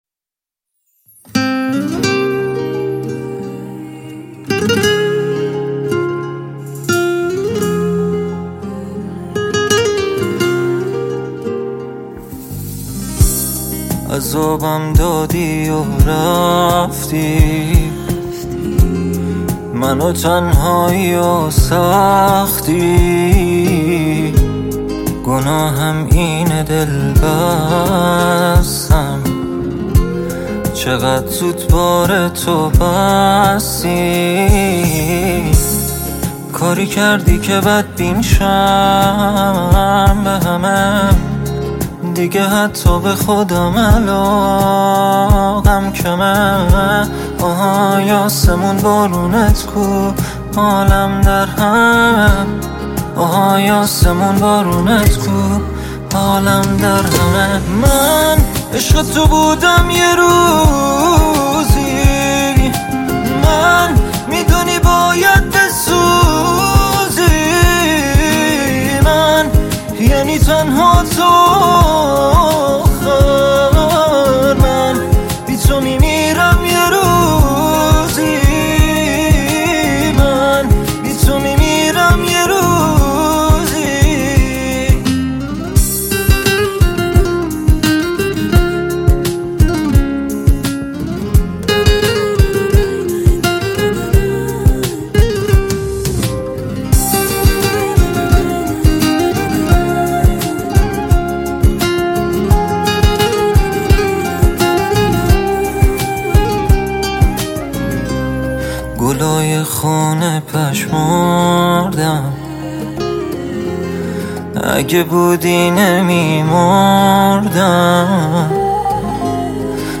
ژانر: ریمیکس